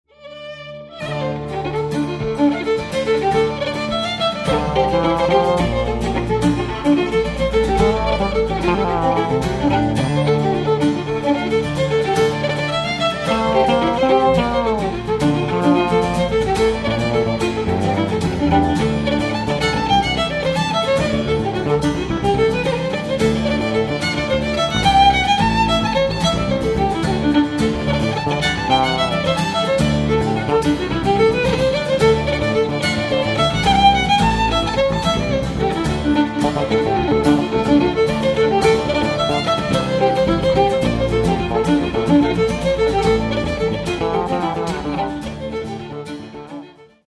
Cape Breton, Irish and Scottish traditional standards